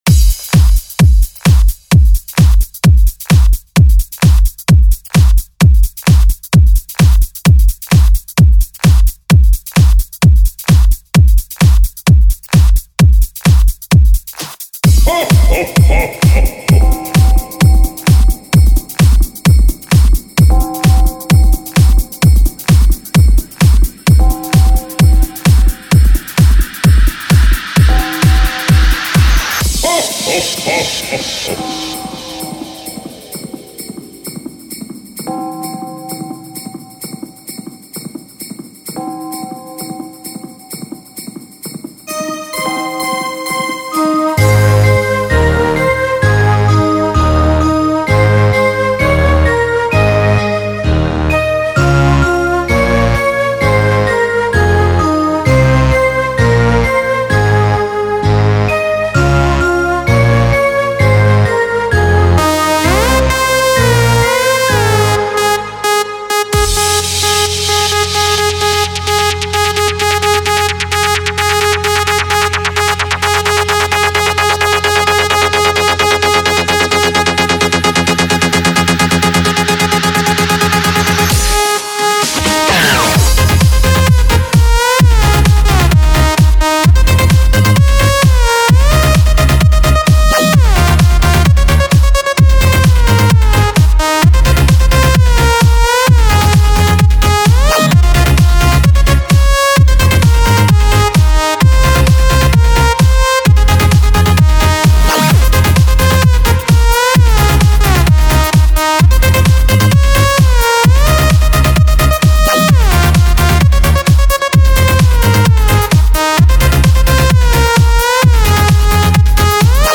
Категория : Dance mix